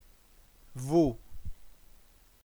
ventesima lettera dell’alfabeto sardo; esprime un suono consonantico (la fricativa labio-dentale sonora, in caratteri IPA [v]); è presente all’inizio e in corpo di parole entrate in sardo da lingue moderne (varzia, vida, scova), e raramente in posizione intervocalica in derivazione da -F- o -PH- latina (Stèvini, scivu).